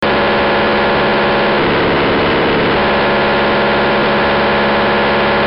Sound of the TETRA signal
sound-tetra-signal-.mp3